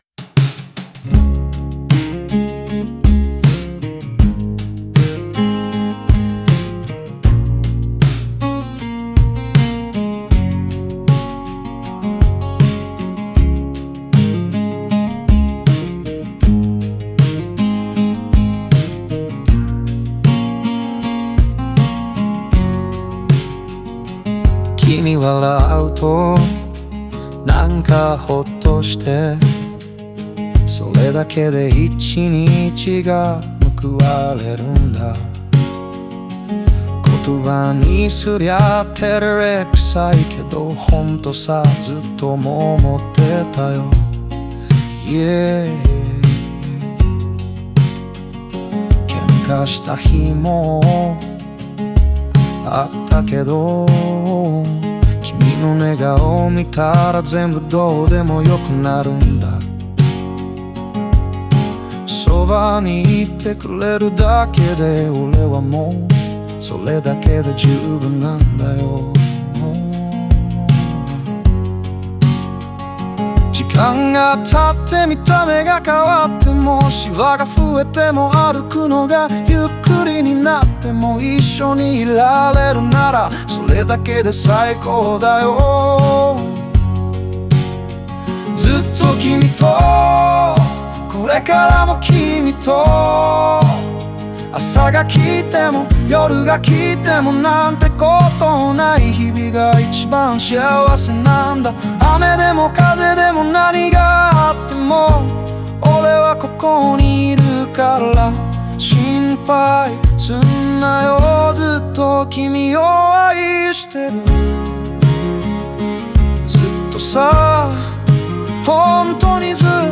オリジナル曲♪
この歌は、長い時を共に過ごす恋人への深い愛情と感謝を、飾らない言葉で綴ったカントリー調のラブソング。